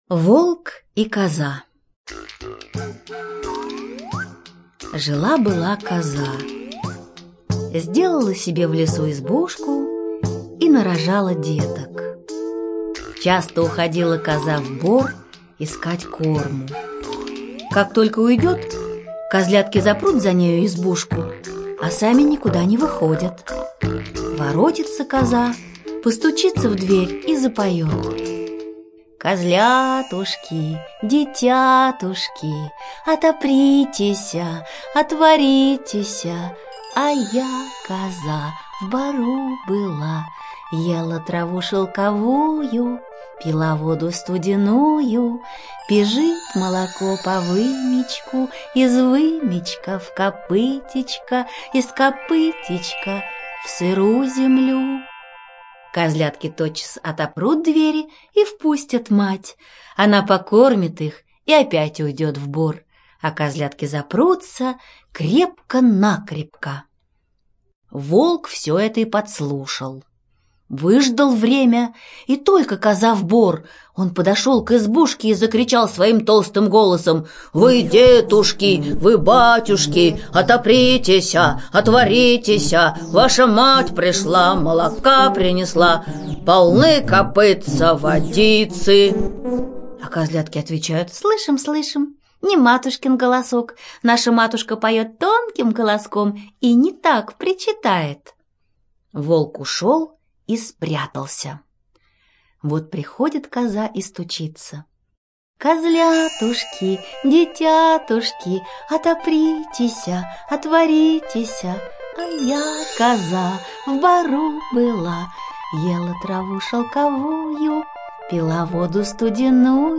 ЧитаетВарлей Н.